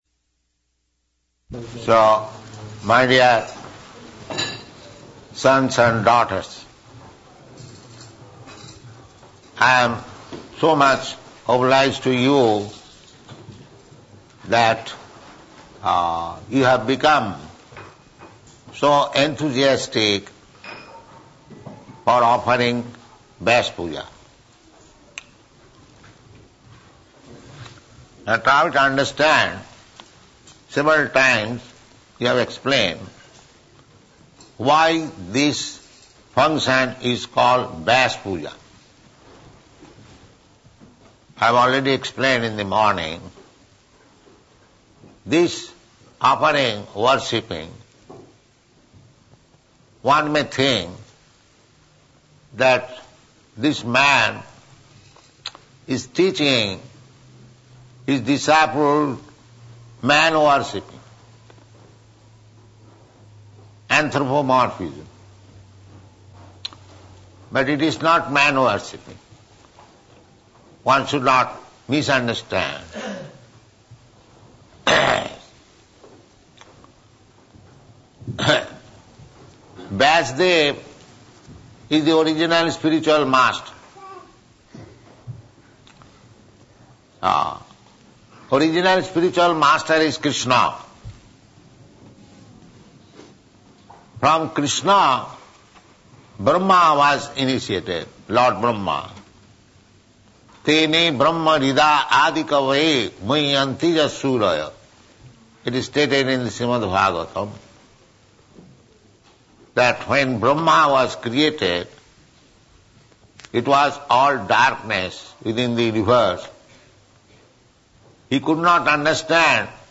Sri Vyasa-puja Lecture by Srila Prabhupada, London, August 22, 1973